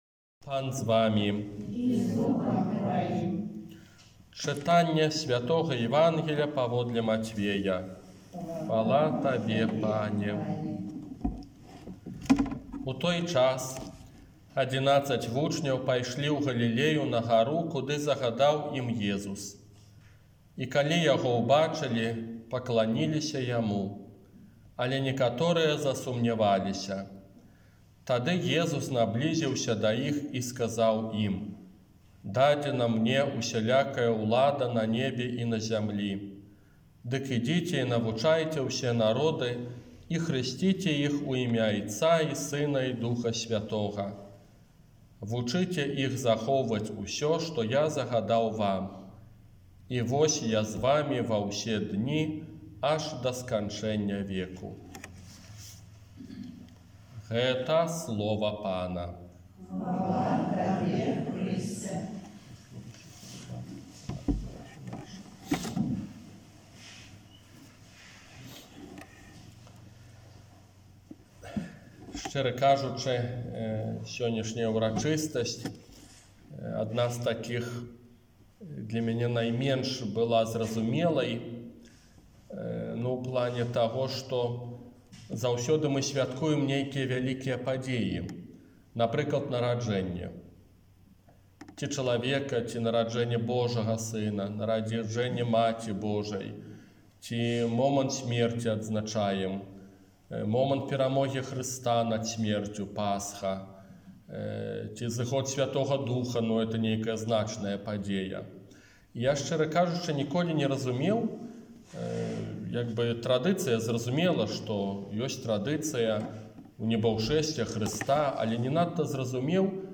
ОРША - ПАРАФІЯ СВЯТОГА ЯЗЭПА
Казанне на ўрачыстасць Унебаўшэсця